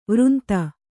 ♪ vřnta